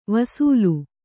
♪ vasūlu